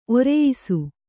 ♪ oreyisu